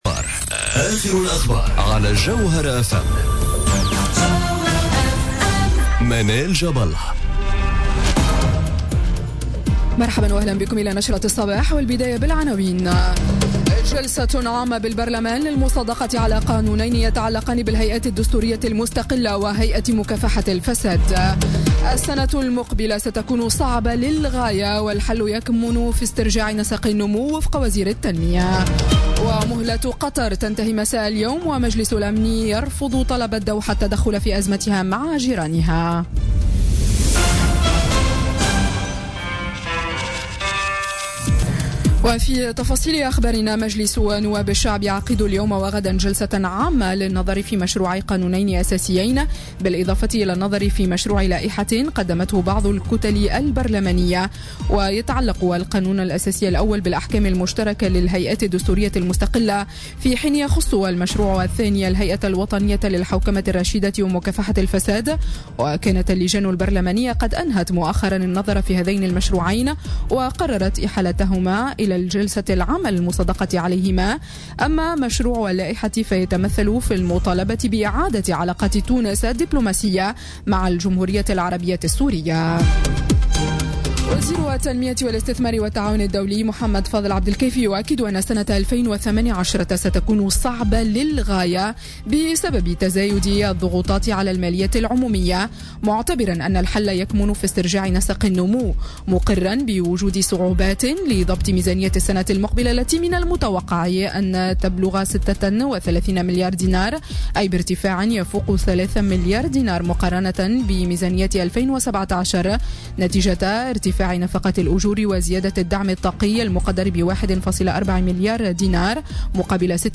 نشرة أخبار السابعة صباحا ليوم الثلاثاء 4 جويلية 2017